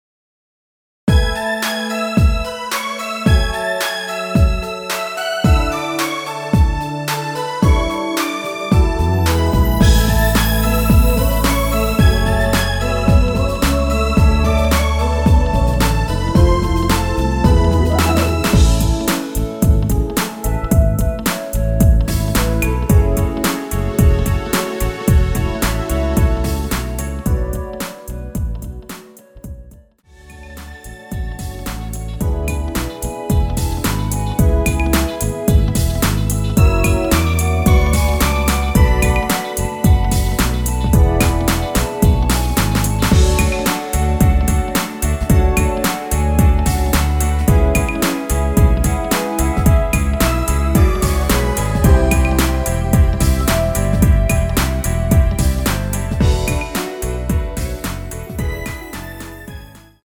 원키에서(+2)올린 멜로디 포함된 (1절+후렴) MR입니다.
앞부분30초, 뒷부분30초씩 편집해서 올려 드리고 있습니다.
중간에 음이 끈어지고 다시 나오는 이유는